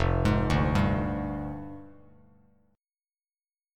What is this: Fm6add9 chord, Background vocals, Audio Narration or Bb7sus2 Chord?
Fm6add9 chord